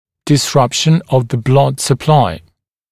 [dɪs’rʌpʃn əv ðə blʌd sə’plaɪ] [дис’рапшн ов зэ блад сэ’плай] нарушение кровоснабжение